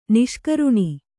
♪ niṣkaruṇi